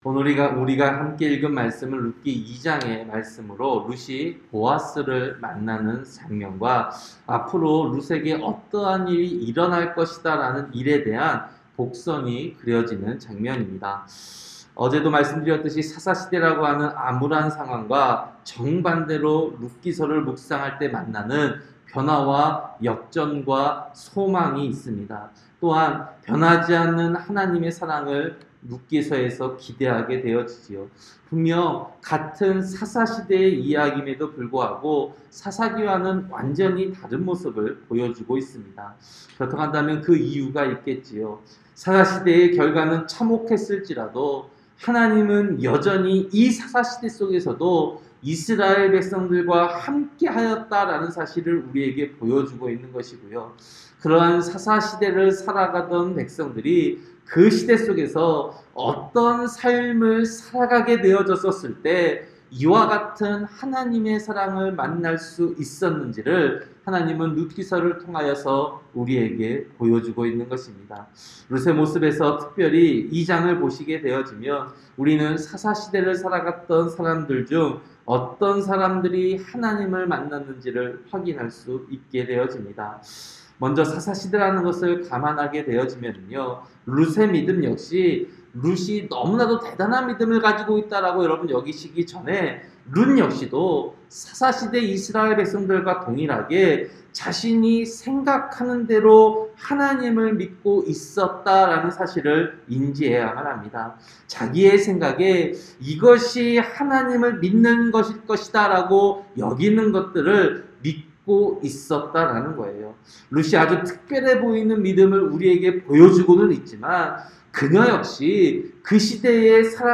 새벽설교-룻기 2장